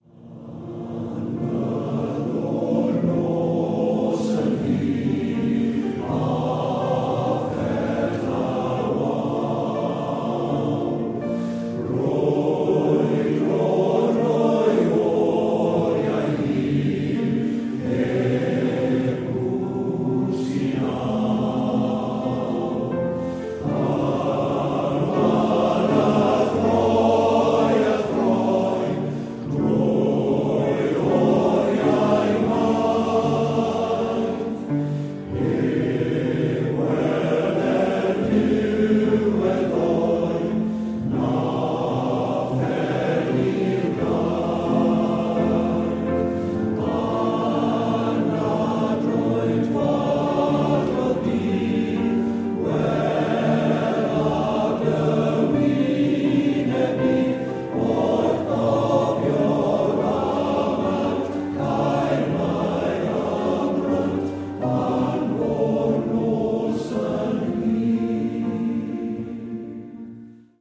OGMORE VALLEY MALE VOICE CHOIR
Think of how the sound of a traditional Welsh Male Voice Choir will set everyone up for a great day of joy and celebrations.